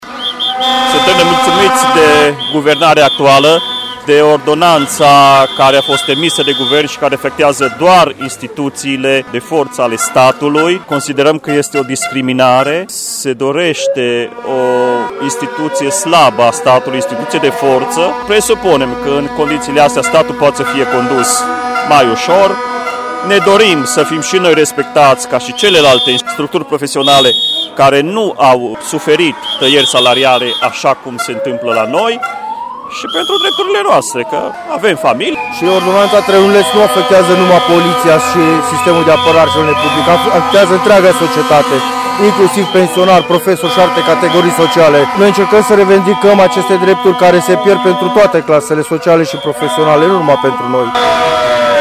Ordonanța ”Trenuleț” afectează cel mai mult instituțiile de forță ale statului, spun protestatarii: